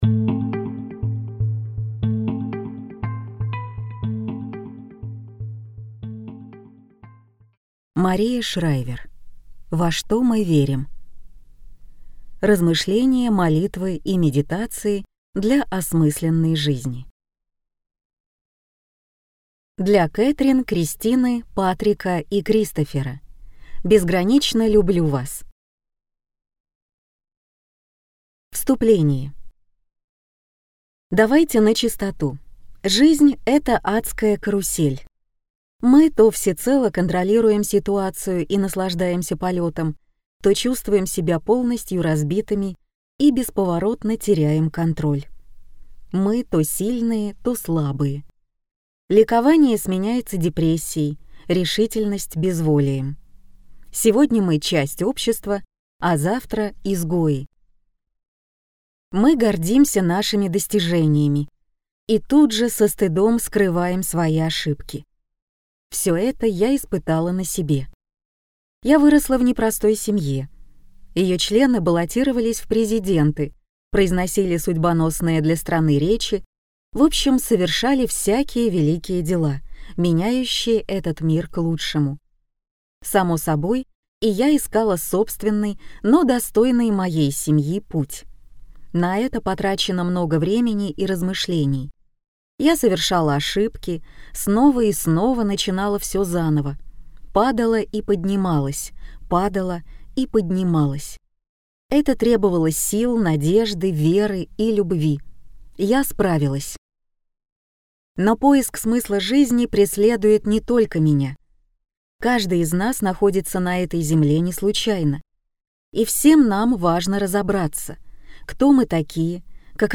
Аудиокнига Во что мы верим. Размышления, молитвы и медитации для осмысленной жизни | Библиотека аудиокниг